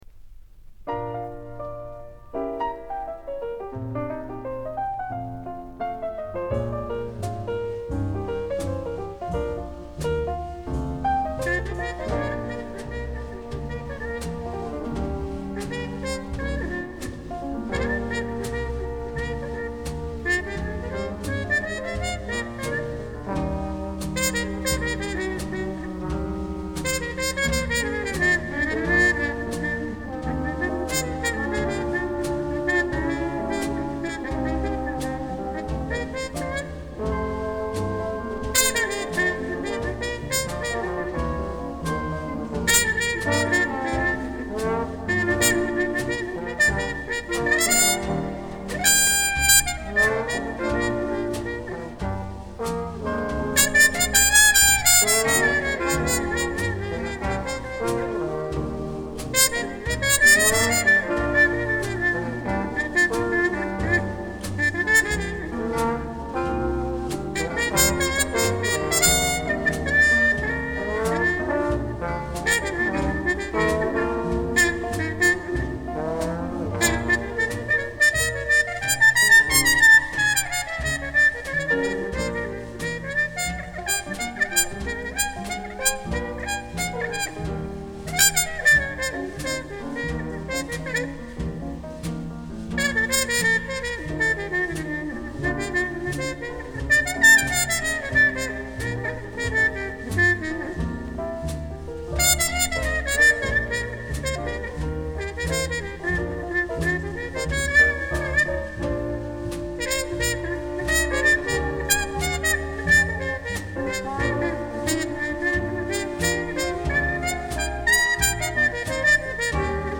• BLUES (JAZZ)
• INSTRUMENTAL BLUES (JAZZ)
• Trumpet
• Trombone
• Clarinet
• Piano
• Bass
• Drums
blues en tempo semi-lent